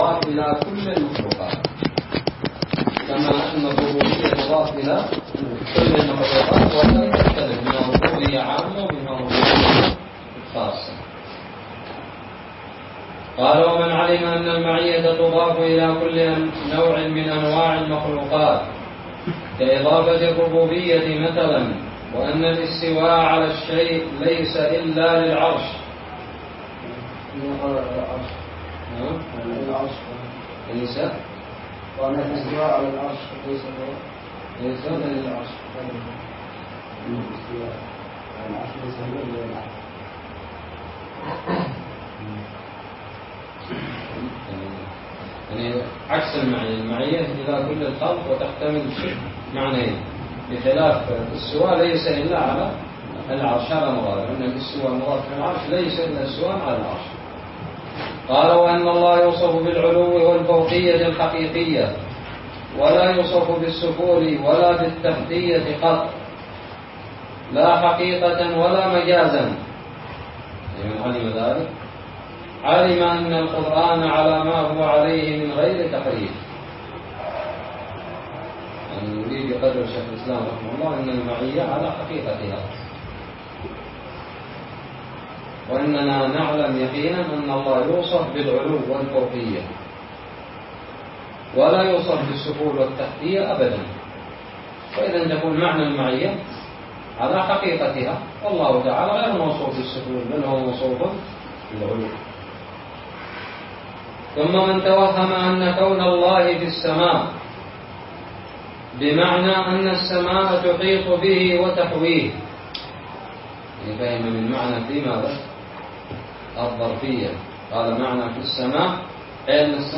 الدرس الثالث والعشرون من شرح متن الحموية
ألقيت في دار الحديث السلفية للعلوم الشرعية بالضالع